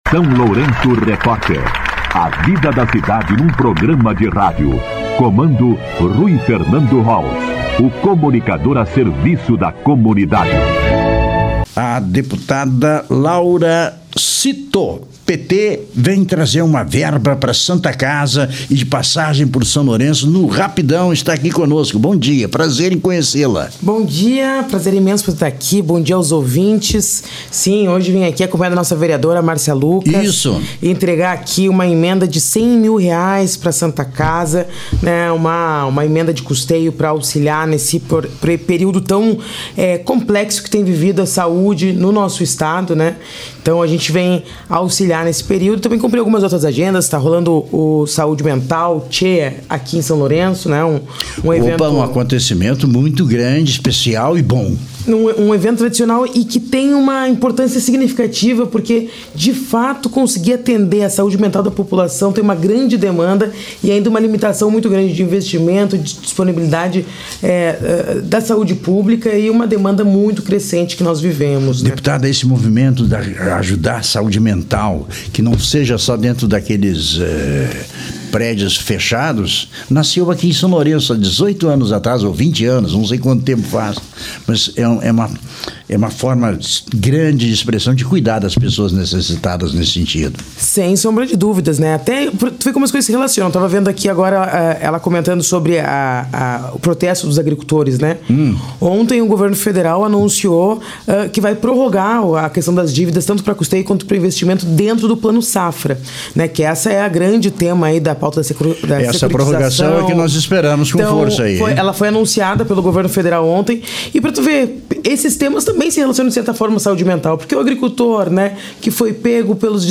Entrevista com Deputada Estadual Laura Sito (PT) e a vereadora Márcia Lucas (PT)